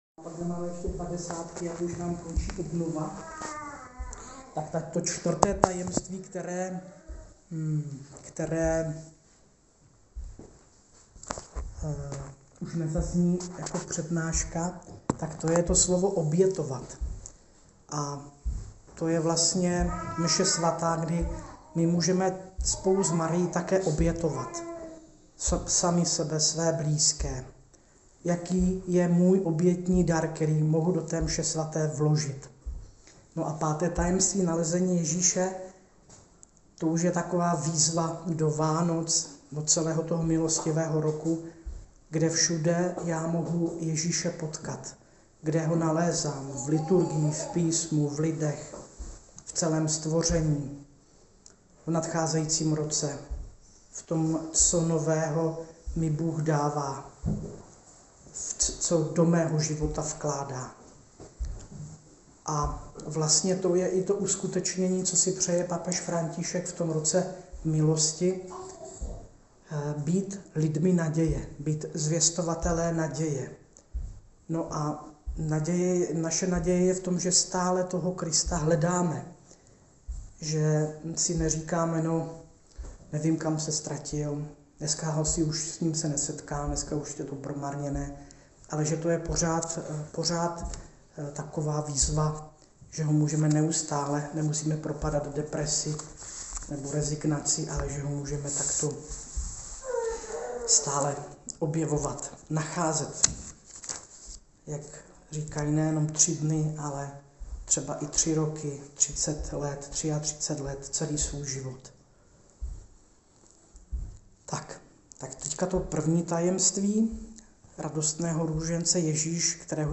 Přednáška